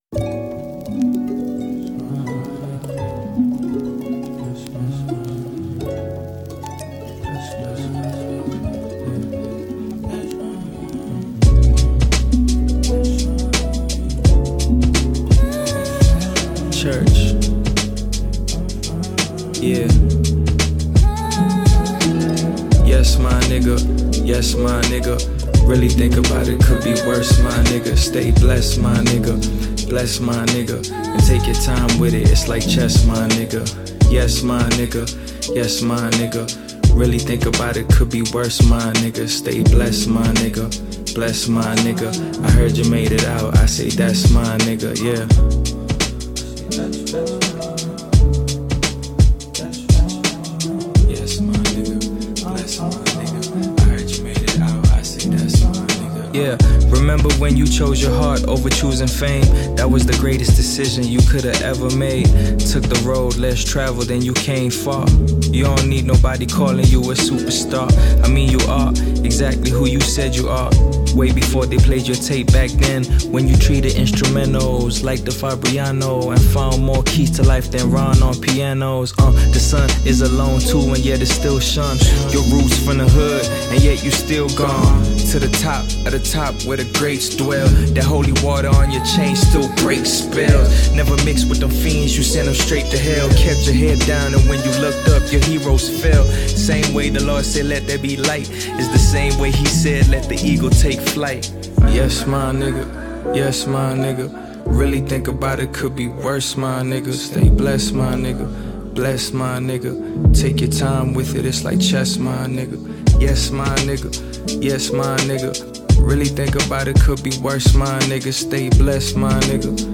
With its infectious beat and captivating vocals